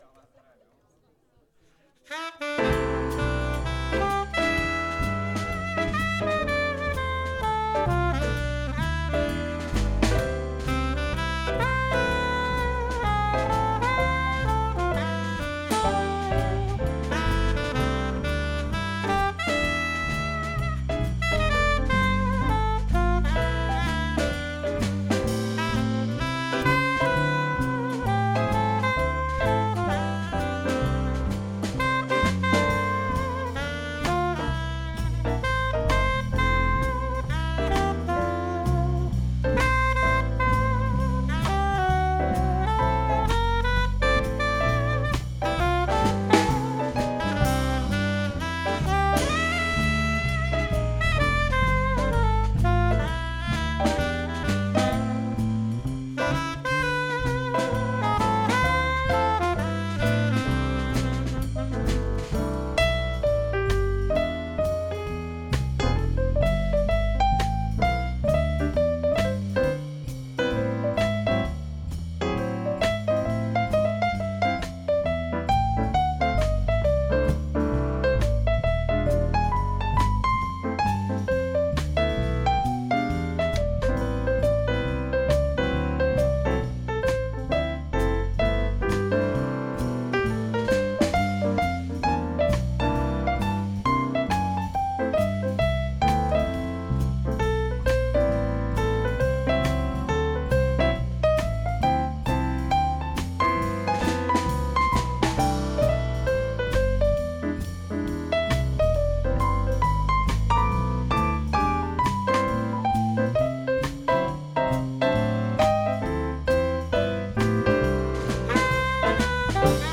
Naskytla se nám možnost zahrát si ve venkovních prostorech kavárny Podnebí.